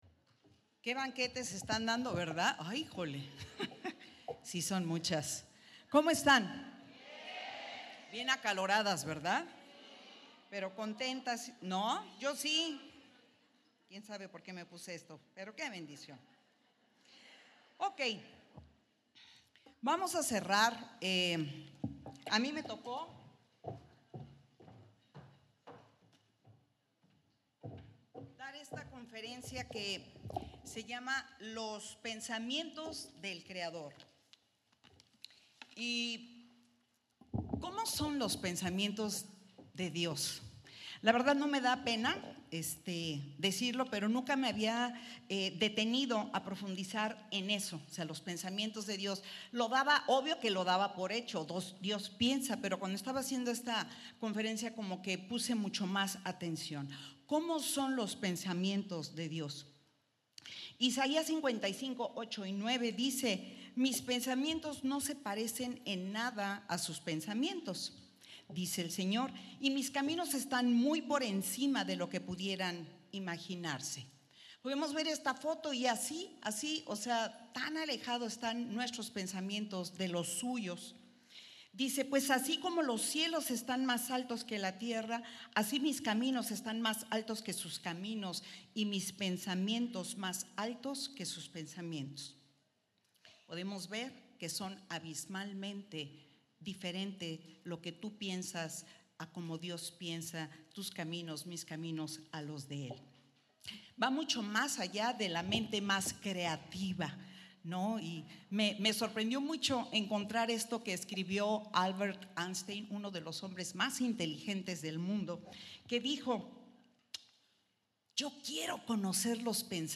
Retiro 2018